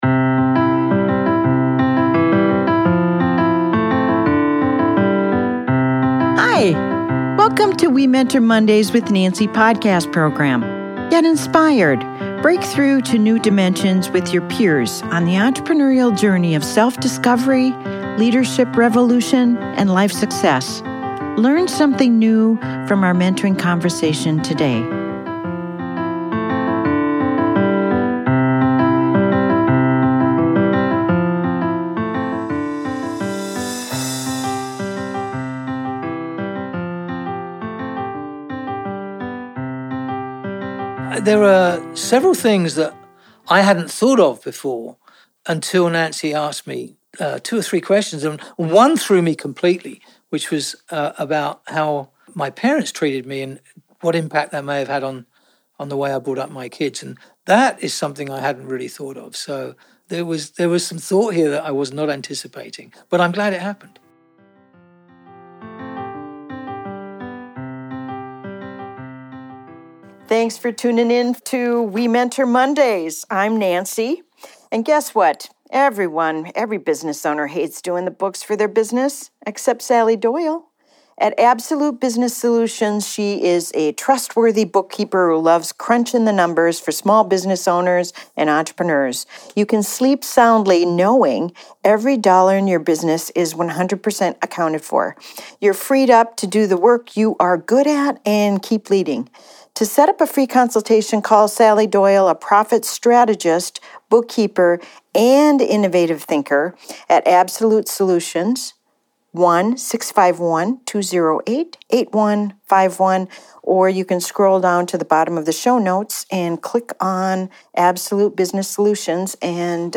The journey with The Wallners brings us to a fun and informative conversation about some of the 52 to 71 gender terms; depending on where you gather research. This conversation isn’t about being ‘right.’ It is about being accurate.